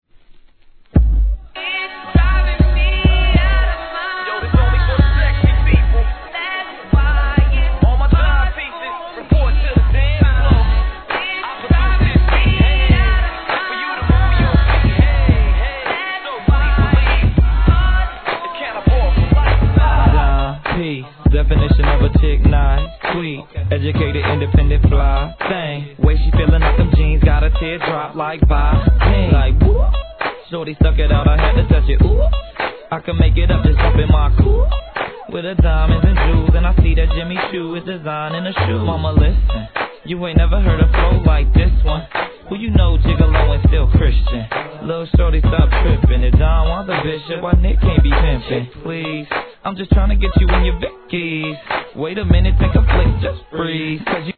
HIP HOP/R&B
2006年、セルフ・プロデュースによるフロア仕様!